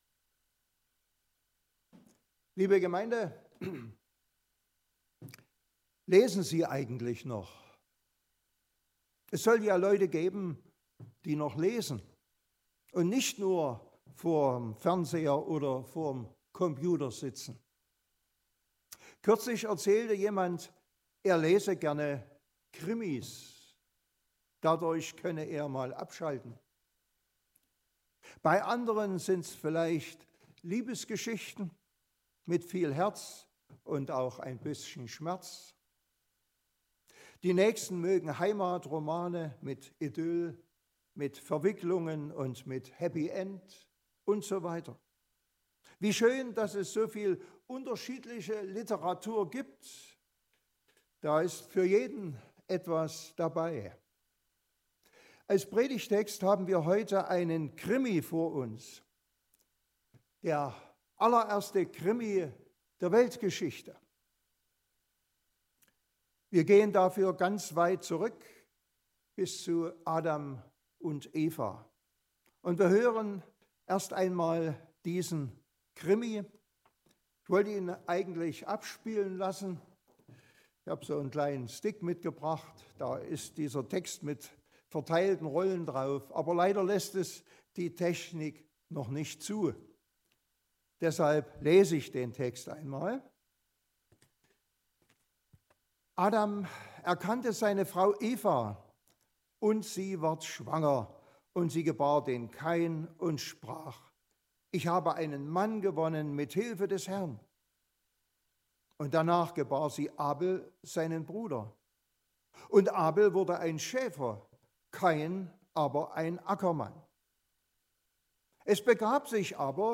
Mose 4, 1-16 Gottesdienstart: Predigtgottesdienst Obercrinitz Heute geht es um einen blutigen Krimi in der Bibel und was was aus diesem lernen können.